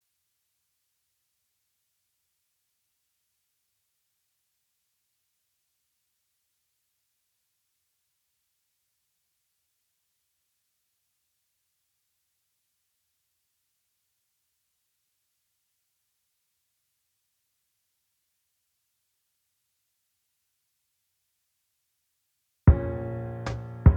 Acoustic Version Pop (2010s) 3:55 Buy £1.50